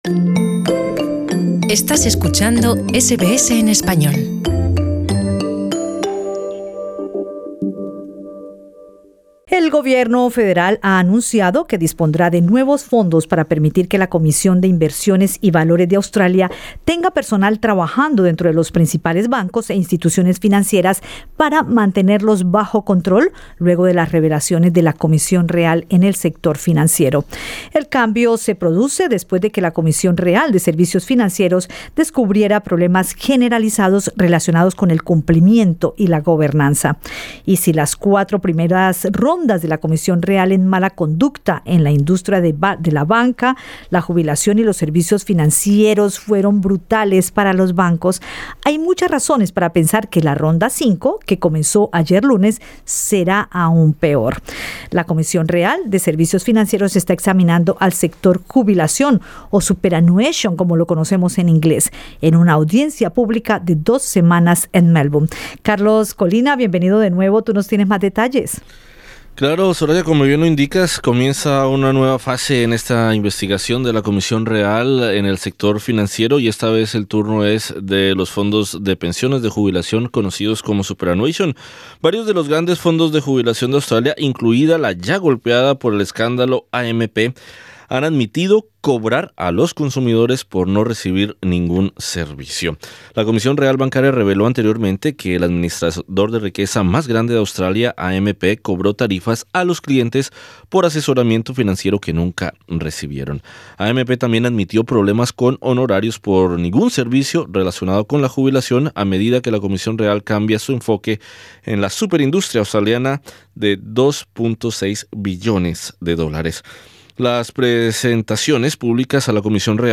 Entrevista con el economista experto en fondos de pensión